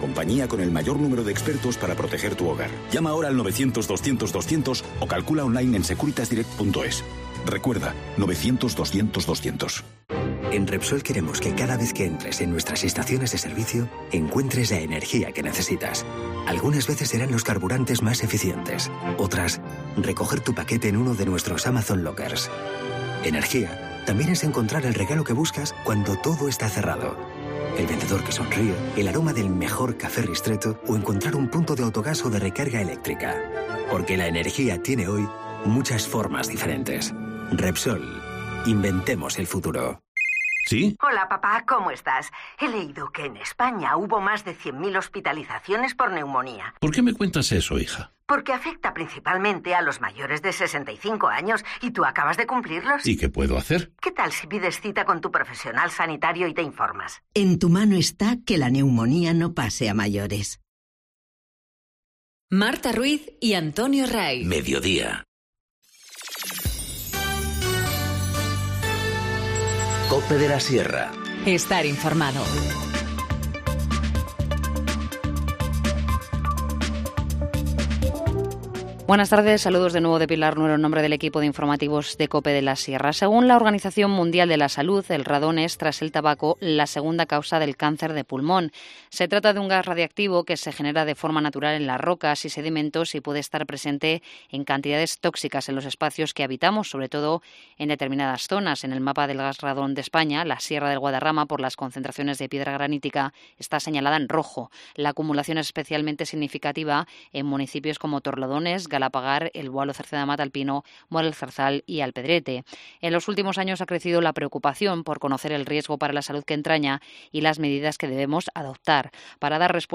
INFORMATIVO MEDIODÍA 7 NOV- 14:50H